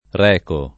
[ r $ ko ]